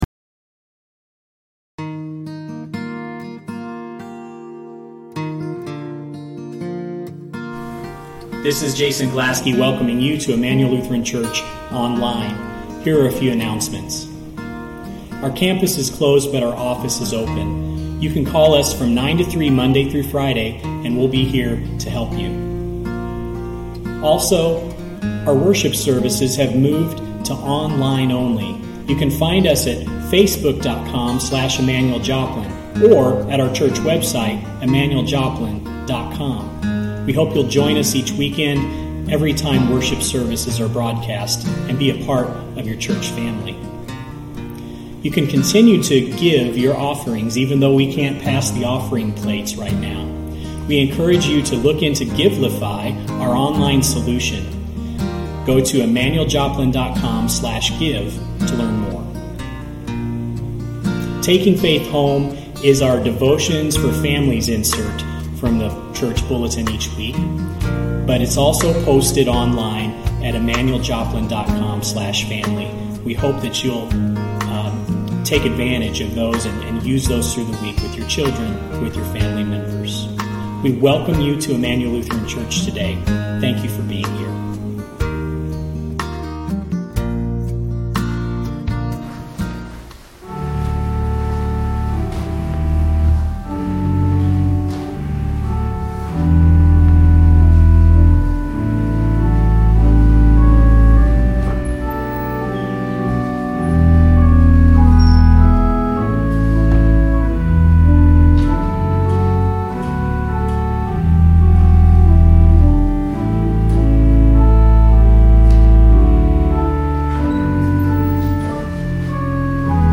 In a Time of Social Distancing | Sermon for March 22, 2020